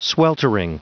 Prononciation du mot sweltering en anglais (fichier audio)
Prononciation du mot : sweltering